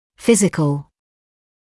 [‘fɪzɪkl][‘физикл]физический; соматический, телесный